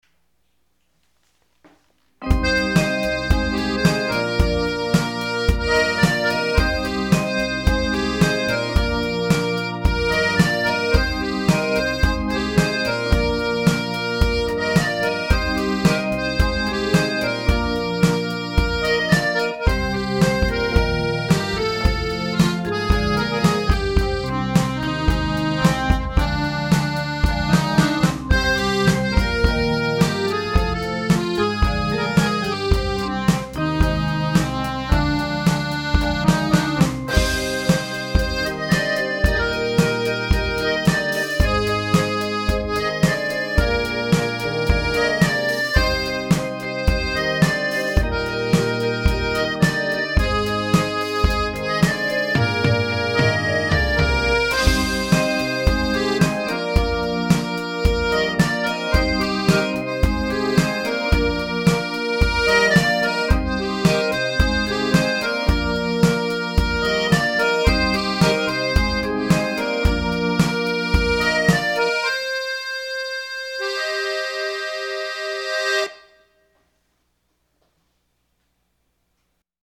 idea of this descending chromatic melody and tried to work it into a